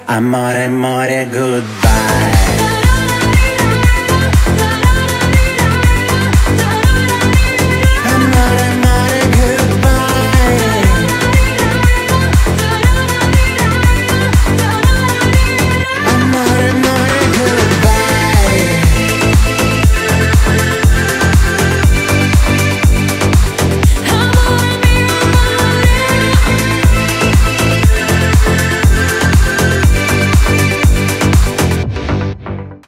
• Качество: 320, Stereo
мужской голос
Club House
летние
яркие